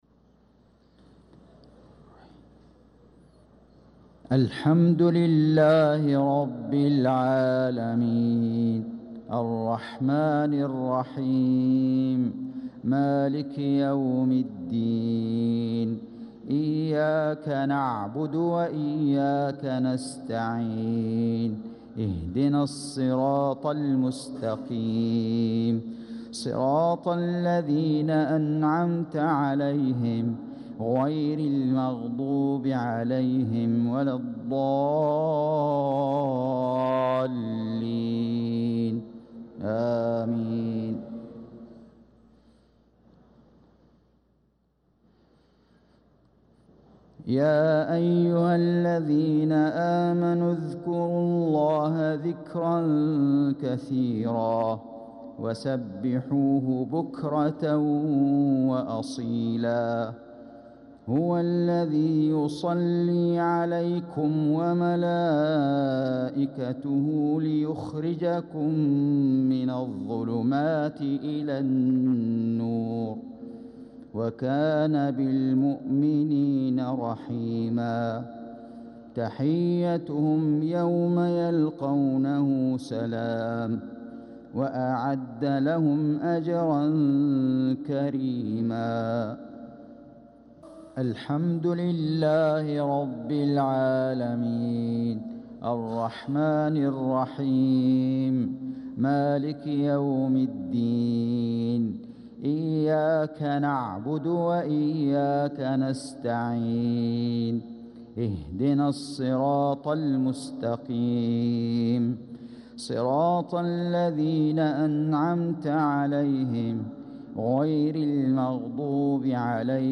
صلاة المغرب للقارئ فيصل غزاوي 20 ذو الحجة 1445 هـ
تِلَاوَات الْحَرَمَيْن .